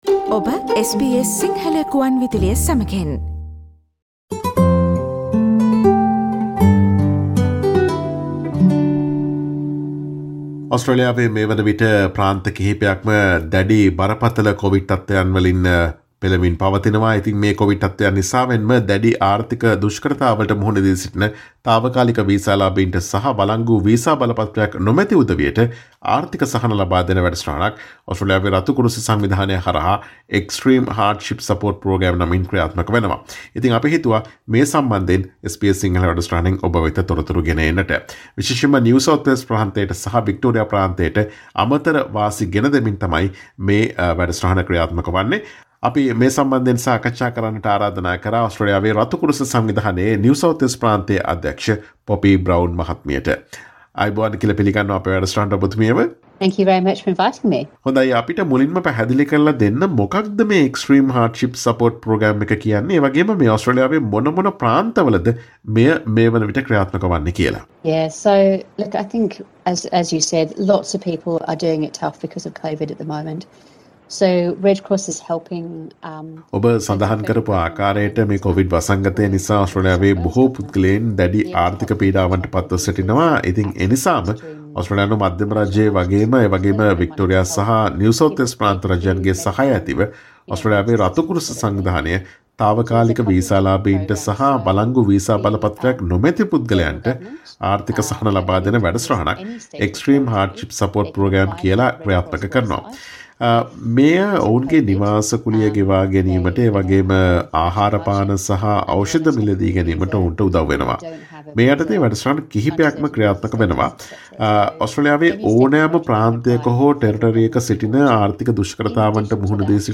ඔස්ට්‍රේලියාවේ Lockdown නිසා ආර්ථික දුෂ්කරතාවන්ට පත් තාවකාලික වීසා ලාභීන්ට සහ වලංගු වීසා බලපත්‍රයක් නොමැති පුද්ගලයින්ට ඔස්ට්‍රේලියාවේ රතු කුරුස සංවිධානය මධ්‍යම රජය සහ වික්ටෝරියා හා නිව් සවුත් වේල්ස් ප්‍රාන්ත රජයන් සමග එක්ව ලබාදෙන ආර්ථික සහන දීමනාව පිළිබඳ SBS සිංහල ගුවන් විදුලිය සිදුකළ සාකච්ඡාවට සවන් දෙන්න.